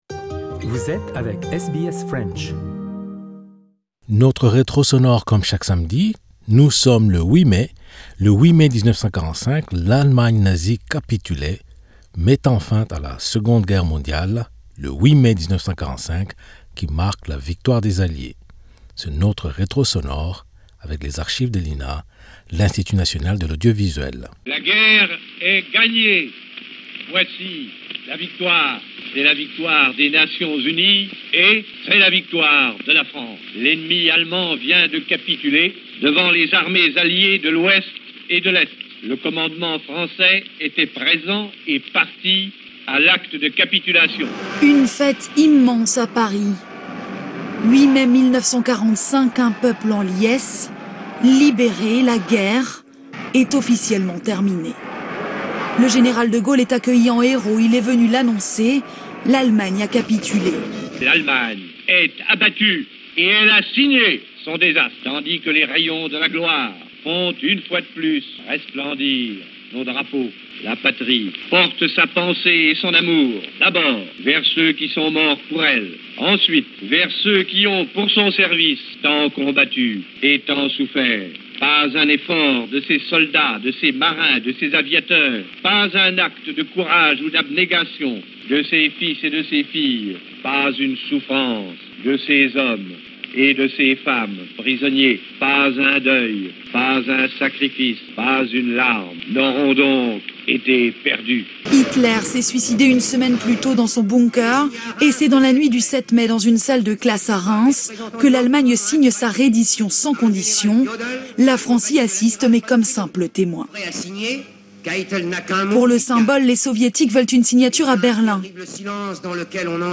Le 8 mai 1945 l'Allemagne nazie capitulait…mettant fin à la seconde guerre mondiale...le 8 mai 1945 qui marque la victoire des Alliés... C'est notre retro sonore...avec les archives de l 'INA...l'Institut National de l'Audiovisuel...